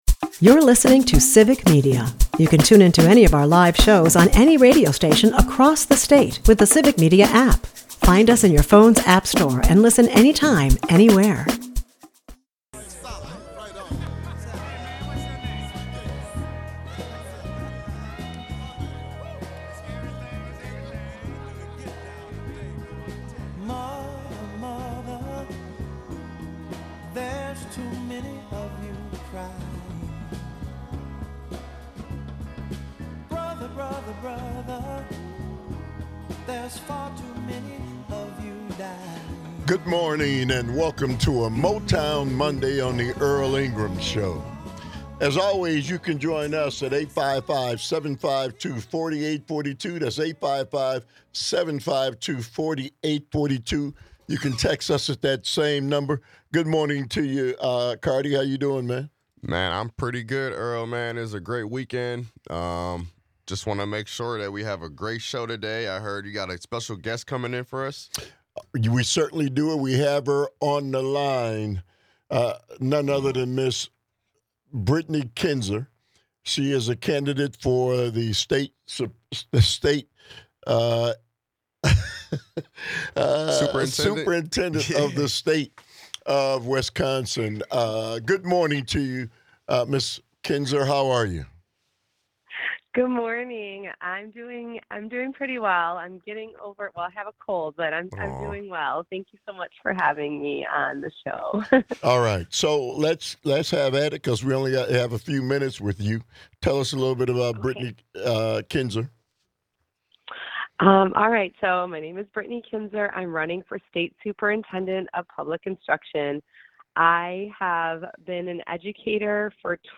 During the interview